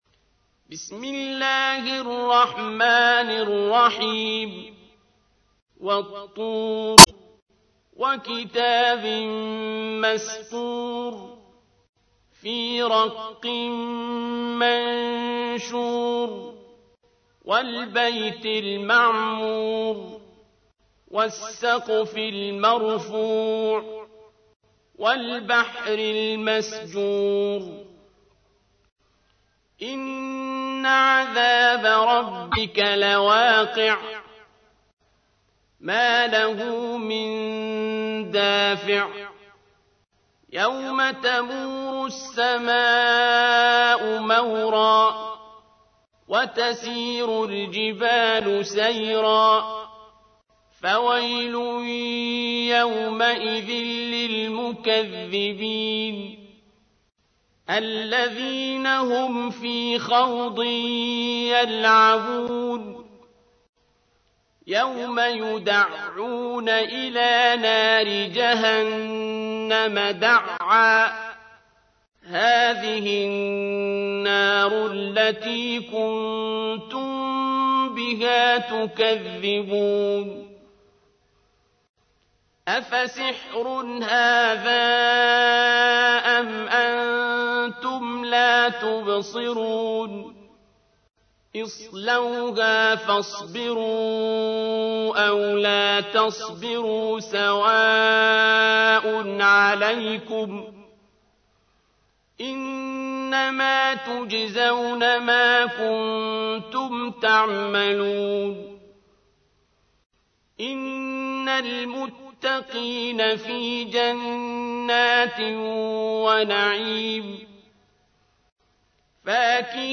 تحميل : 52. سورة الطور / القارئ عبد الباسط عبد الصمد / القرآن الكريم / موقع يا حسين